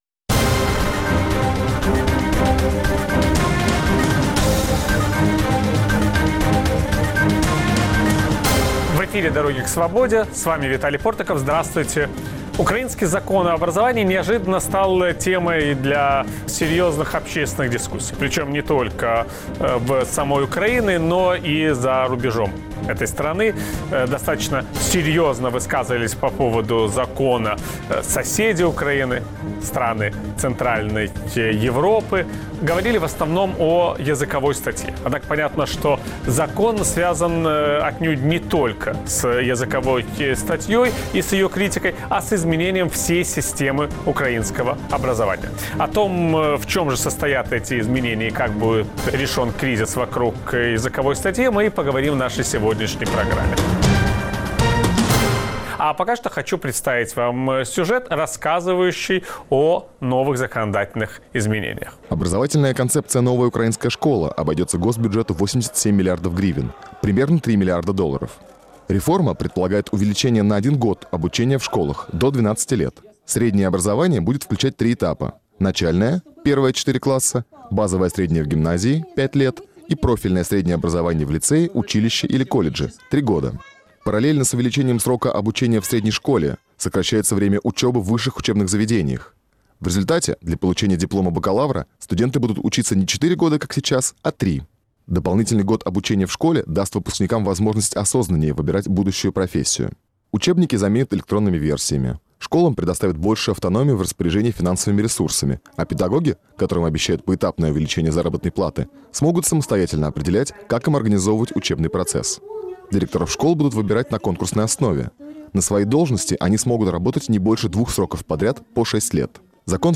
беседует